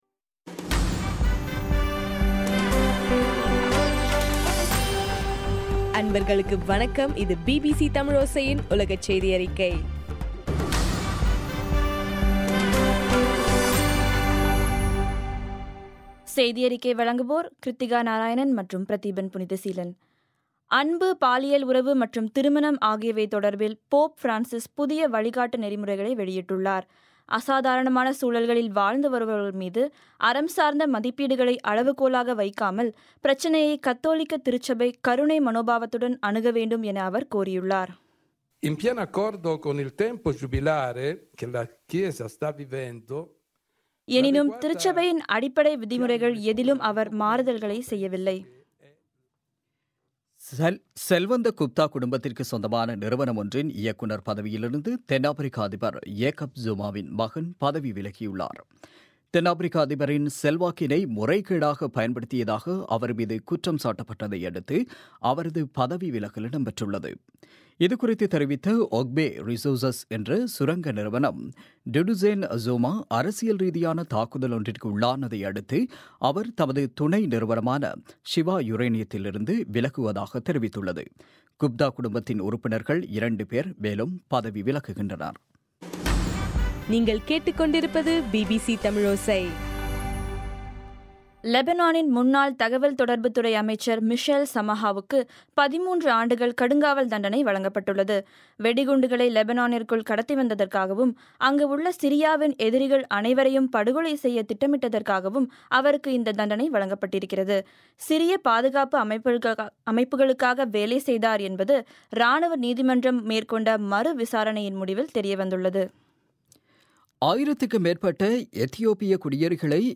8 ஏப்ரல், 2016 பிபிசி செய்தியறிக்கை